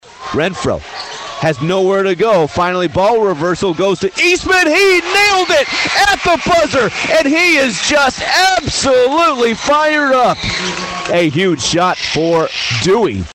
Nowata hosted rival Dewey in a pair of basketball contests, with coverage of the boys game on KRIG 104.9.
Dewey shot against nowata.mp3